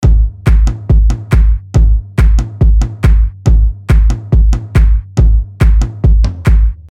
踢顶拍手
标签： 140 bpm House Loops Drum Loops 1.15 MB wav Key : Unknown
声道立体声